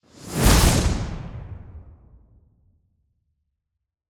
Play, download and share korku2 original sound button!!!!
es_magic-spell-whoosh-2-sfx-producer_2HiiaXS.mp3